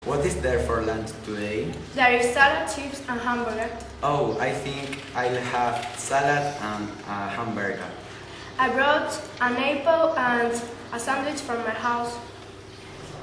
Chico y chica sentados en mesa frente a ordenador mantienen una conversación.